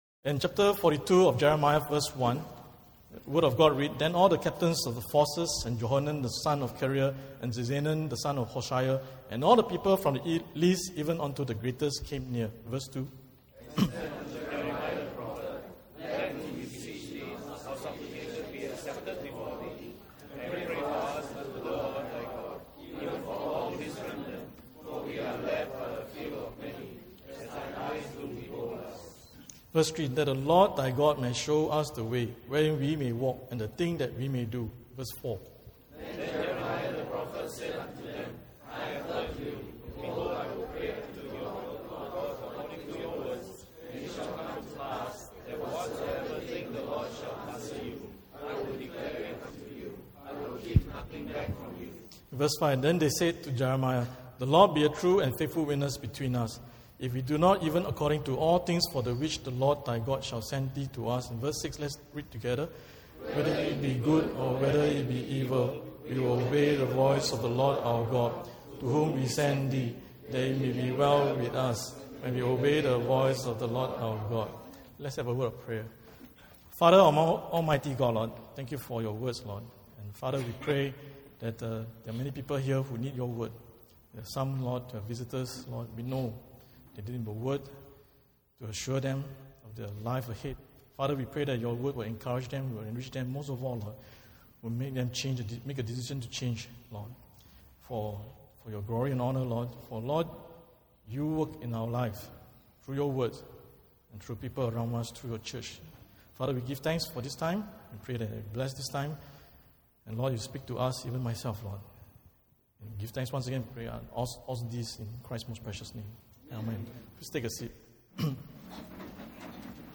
Sunday Worship Service